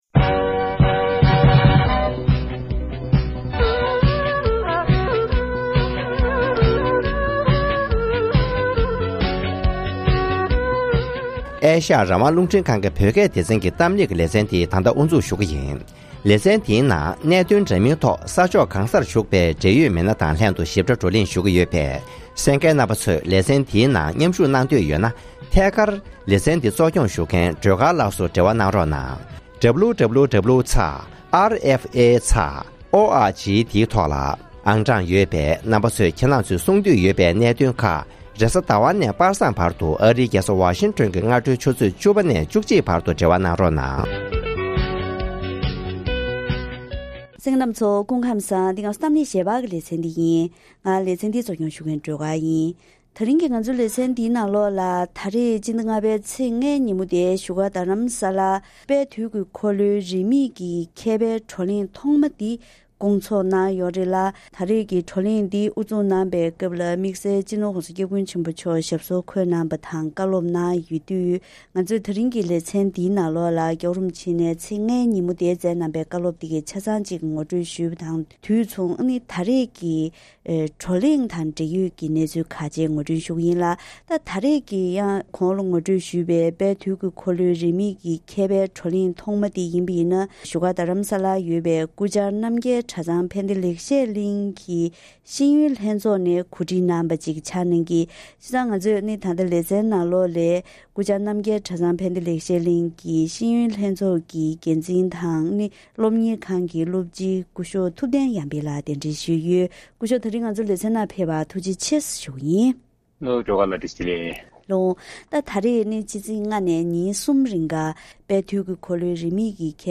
༄༅༎དེ་རིང་གི་གཏམ་གླེང་ཞལ་པར་ལེ་ཚན་ནང་དཔལ་དུས་ཀྱི་འཁོར་ལོའི་རིས་མེད་བགྲོ་གླེང་ཐོག་མ་འདི་བཞུགས་སྒར་རྡ་རམ་ས་ལར་ཚོགས་ཡོད་ཅིང་།